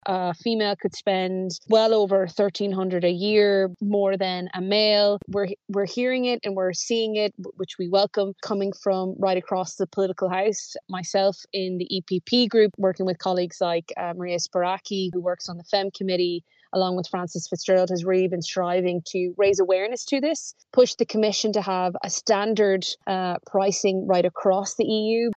MEP Maria Walsh believes there should be standard pricing across the board: